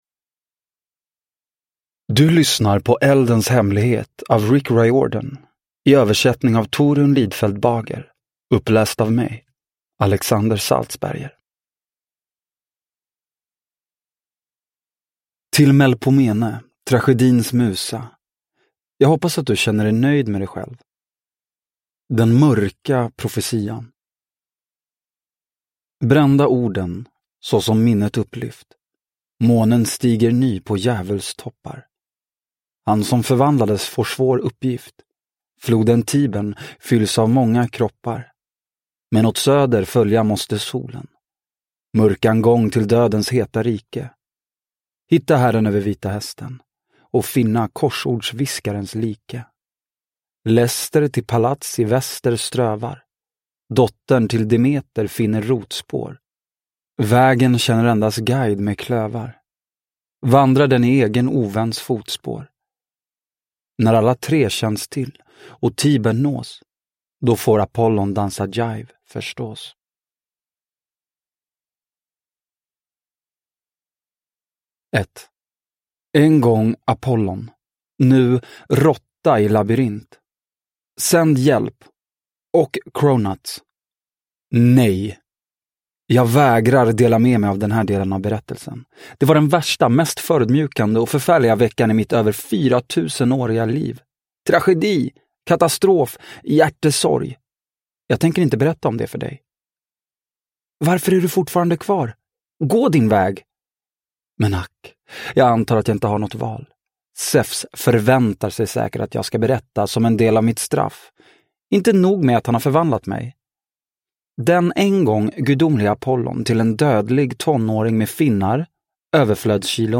Eldens hemlighet – Ljudbok – Laddas ner